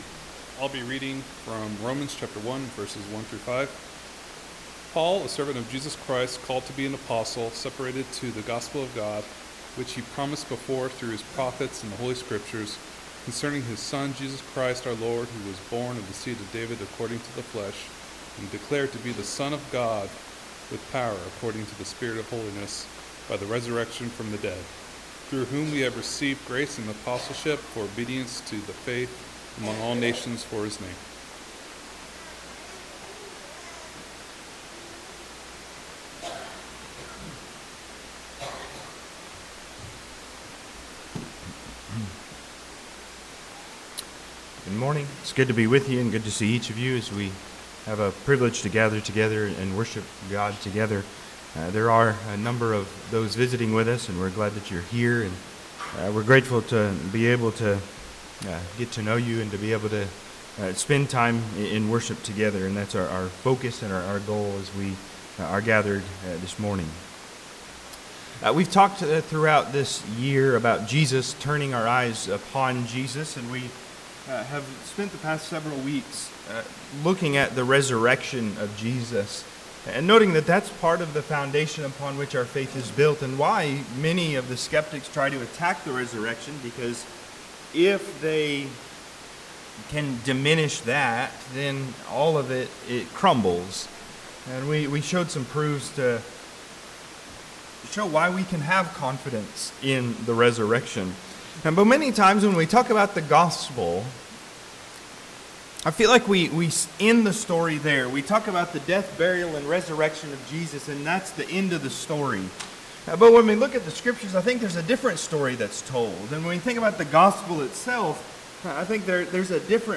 Romans 1:1-5 Service Type: Sunday AM Topics